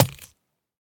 Minecraft Version Minecraft Version snapshot Latest Release | Latest Snapshot snapshot / assets / minecraft / sounds / item / trident / pierce3.ogg Compare With Compare With Latest Release | Latest Snapshot